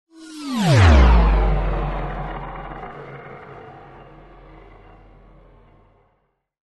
На этой странице собраны звуки космических ракет: от рева двигателей при старте до гула работы систем в открытом космосе.
Звук ракеты в полете сквозь космос